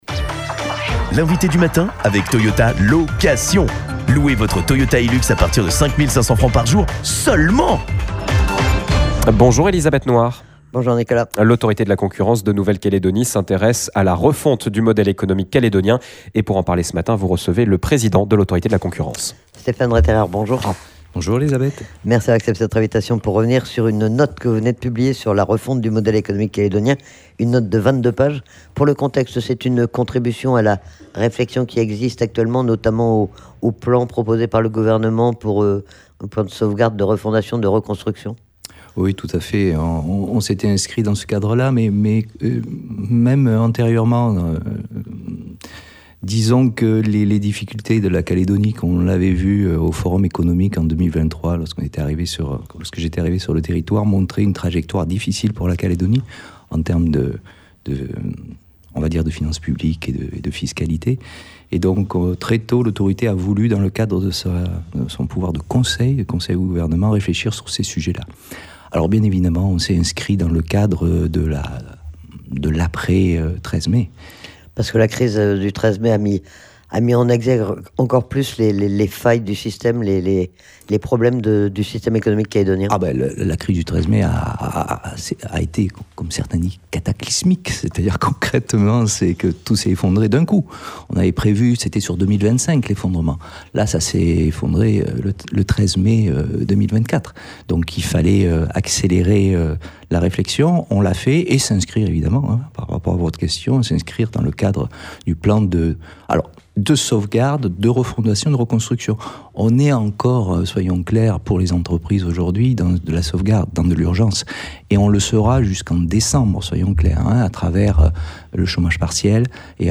Nous en avons parlé avec Stéphane Retterer, président de l’Autorité de la Concurrence en Nouvelle-Calédonie.